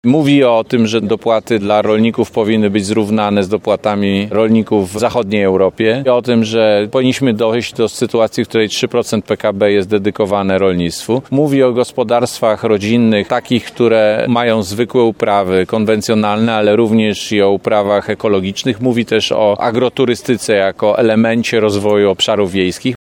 Parlamentarzysta wraz z samorządowcami oraz zwolennikami kandydatury Rafała Trzaskowskiego na urząd Prezydenta RP, podczas konferencji na Placu Bartosza Głowackiego, dziękował mieszkańcom za udział w I turze wyborów, jednocześnie namawiał do udziału w II turze głosowania.